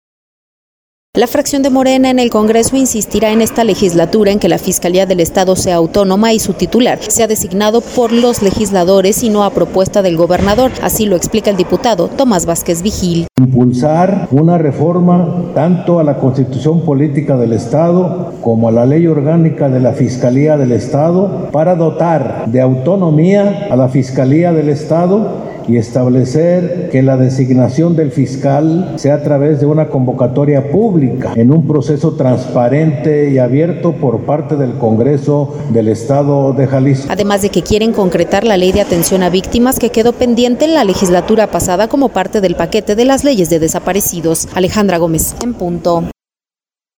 La fracción de Morena en el Congreso, insistirá en esta legislatura, en que la Fiscalía del Estado sea Autónoma, y su titular, sea designado por los legisladores, y no a propuesta del gobernador. Así lo explica el diputado Tomás Vázquez Vigil: